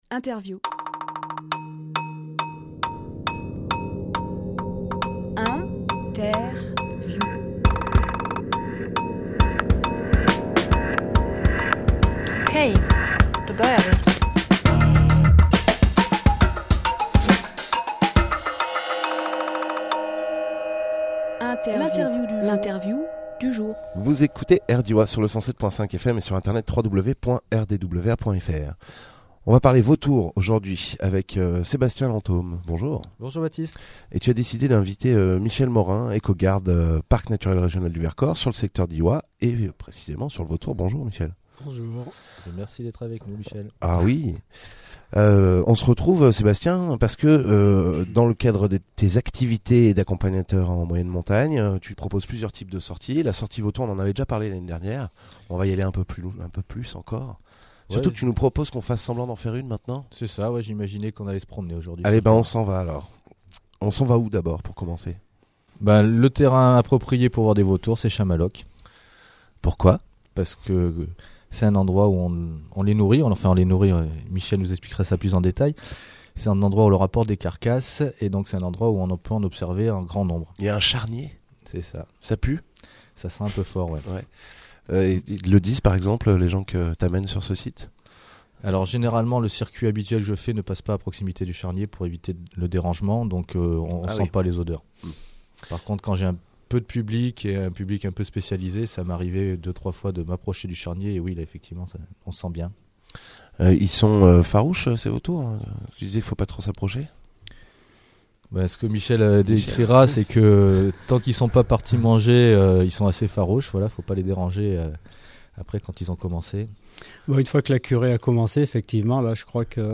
Emission - Interview Les Vautours à Chamaloc Publié le 20 décembre 2018 Partager sur…